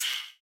Breath(1).wav